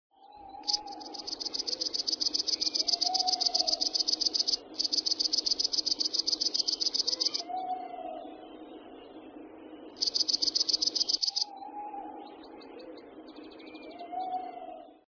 Heuschrecke
heuschrecke.mp3